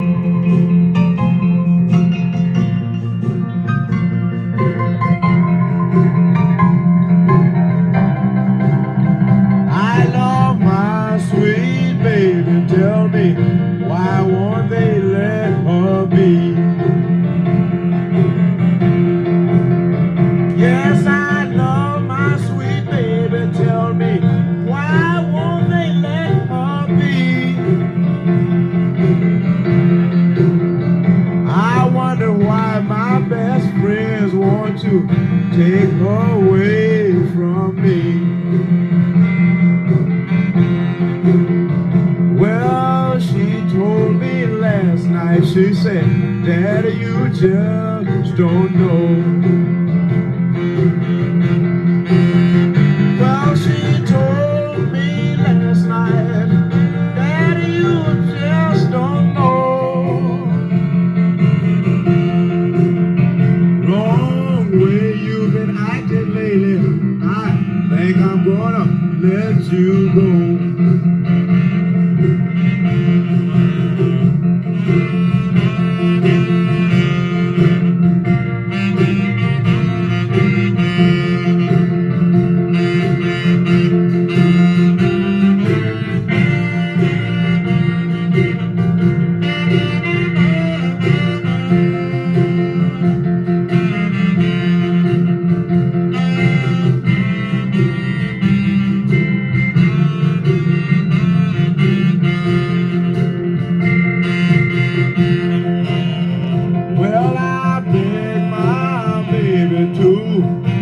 ジャンル：BLUES
店頭で録音した音源の為、多少の外部音や音質の悪さはございますが、サンプルとしてご視聴ください。